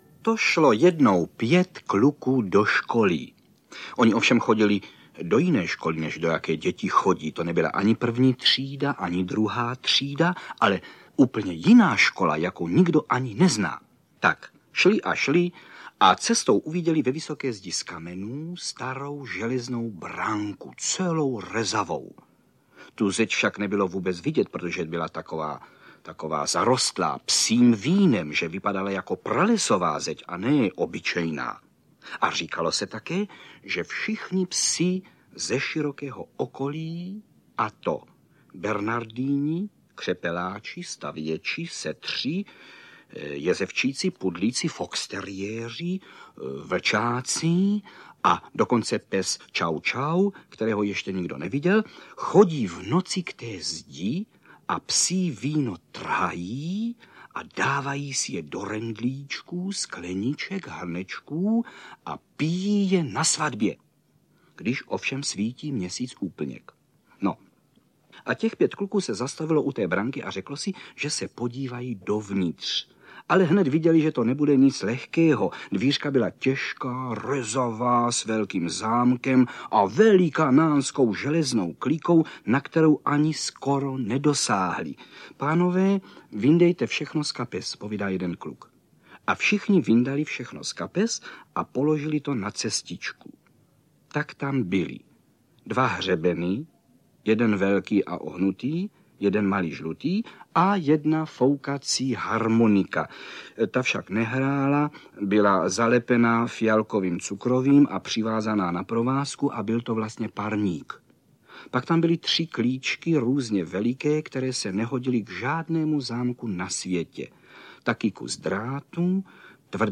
Zahrada audiokniha
Ukázka z knihy
K umělcovu výročí jsme rádi upozornili na jeho mimořádnou dětskou knihu, čtenou Karlem Högerem. Hercova interpretace souzní s autorovým textem, když mistrně rozehrává nuance nevysvětlitelných záhad kouzelné zahrady, takže poslech může být zážitkem nejen pro děti, ale i jejich rodiče.
• InterpretKarel Höger